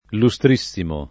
vai all'elenco alfabetico delle voci ingrandisci il carattere 100% rimpicciolisci il carattere stampa invia tramite posta elettronica codividi su Facebook lustrissimo [ lu S tr &SS imo ] s. m. — cfr. illustre — sim. il cogn.